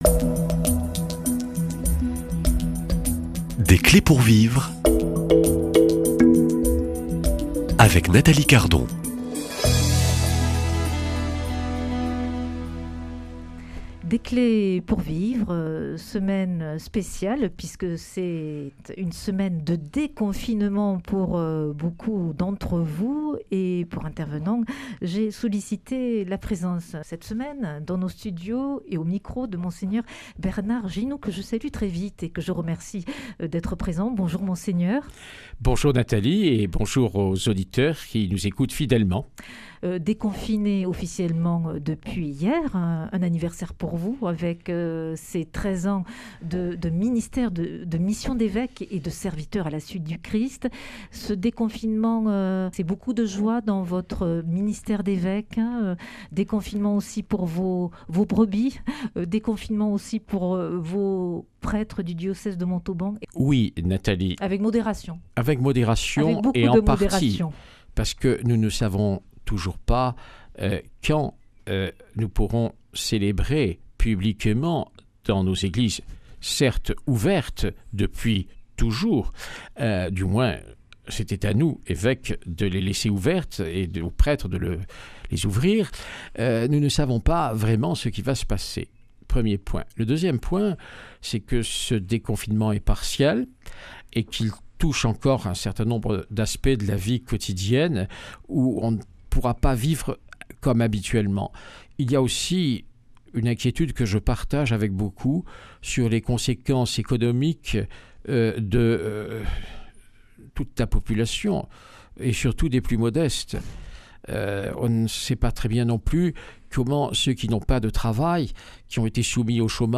Invité : Mgr Bernard Ginoux (Évêque du Diocèse de Montauban)